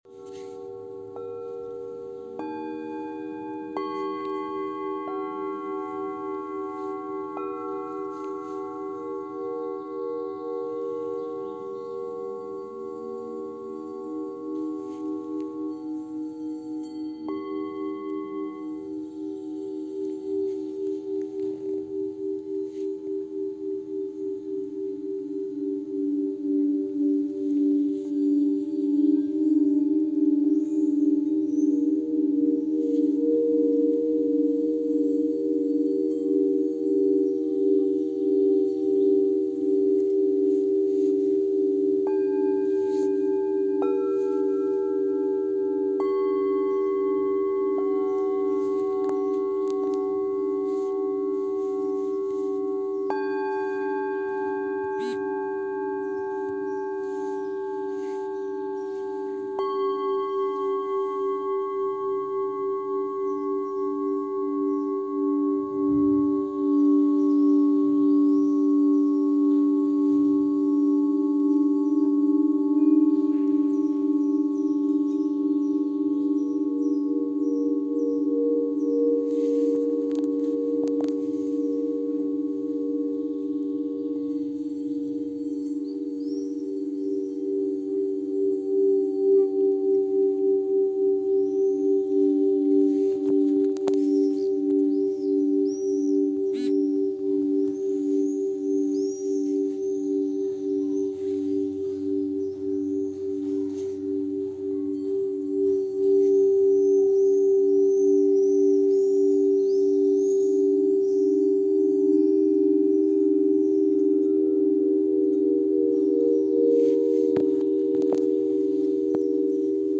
Massage sonore au cours collectif du 23 juin 2021 à la MJC Desforges à Nancy
massage-sonore-.mp3